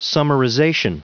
Prononciation du mot summarization en anglais (fichier audio)
Prononciation du mot : summarization